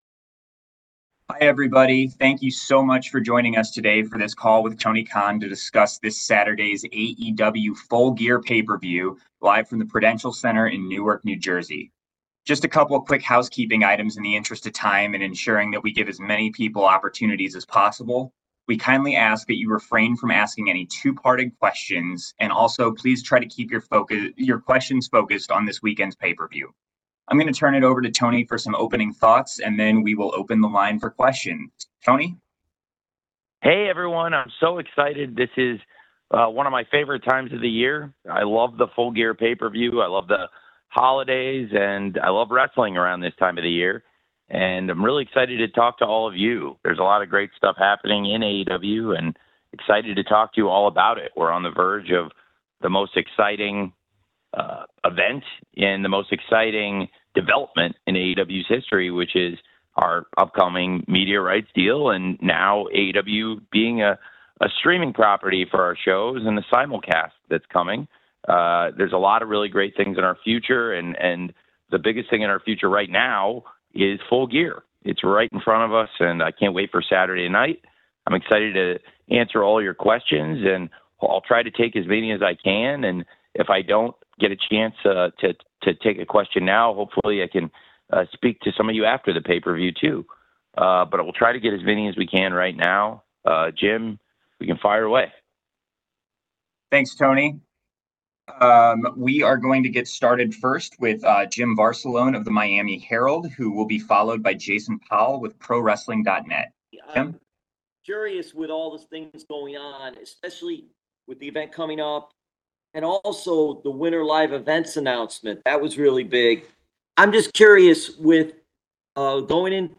Media call with Tony Khan on AEW Full Gear, Big Boom AJ, AEW streaming on MAX, Australia, new live event strategy and more.
Tony Khan the President, General Manager and Head of Creative for All Elite Wrestling spoke to the media on Thursday November 21, 2024 to discuss one of his PPVs — Full Gear.